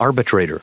Prononciation du mot arbitrator en anglais (fichier audio)
Prononciation du mot : arbitrator